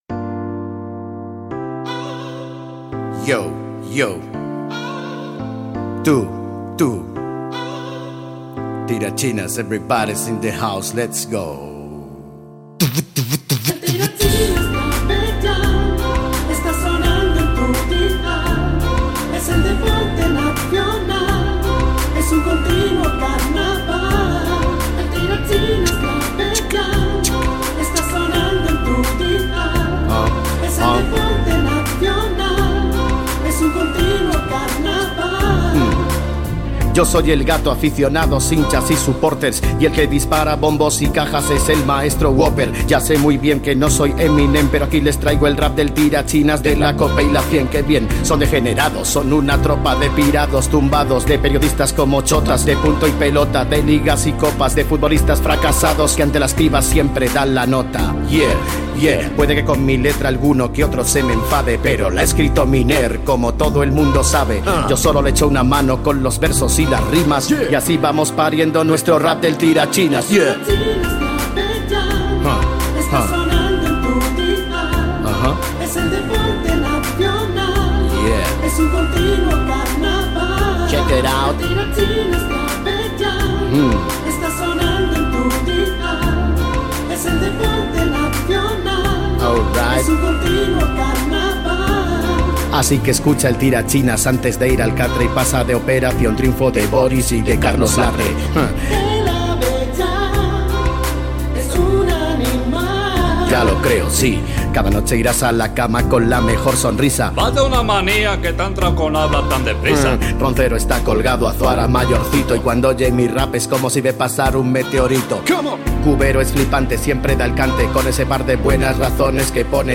Cançó rap del programa.
Programa presentat per José Antonio Abellán.